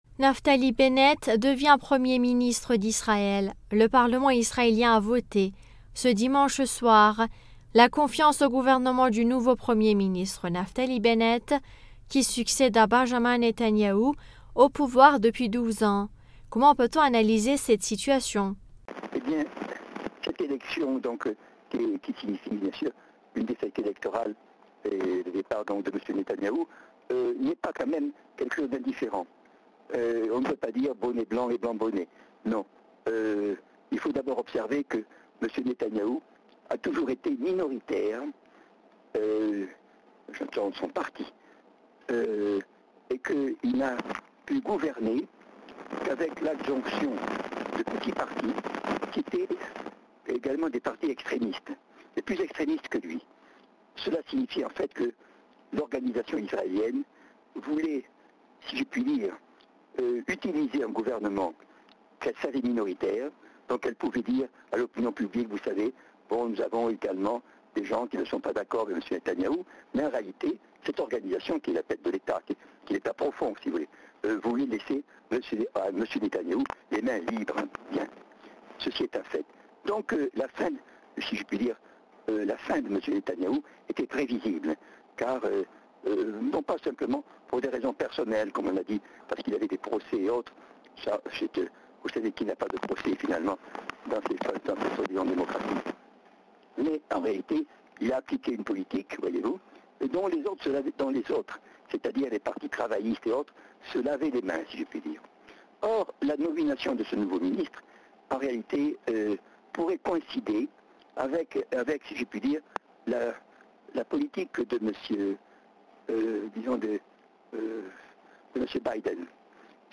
politologue français nous répond.